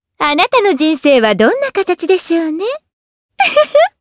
Shamilla-Vox_Skill2_jp.wav